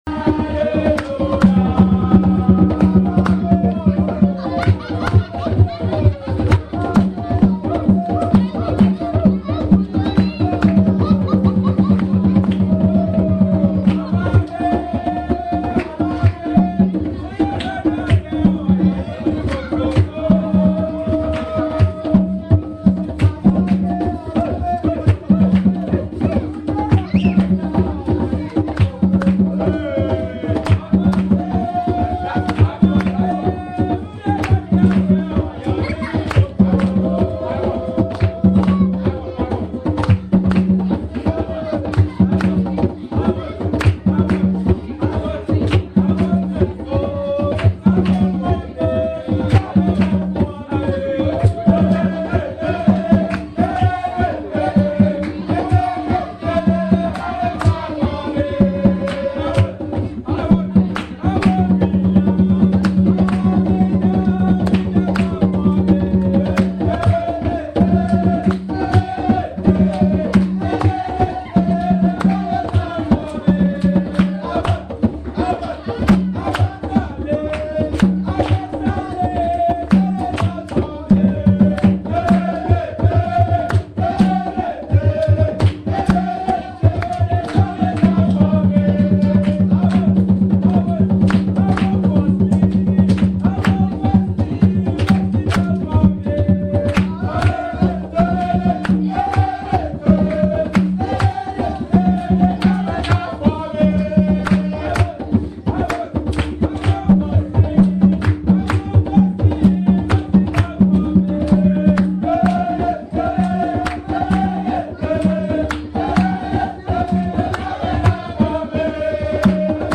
The local kids came out and entertained us with their drumming and dancing, which I managed to record.
Here are two recordings from that evening.
Figure 10. The Gazebo where the evening entertainment took place